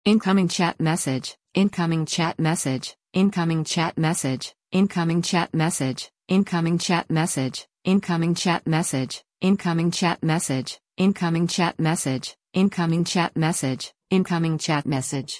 message_received.mp3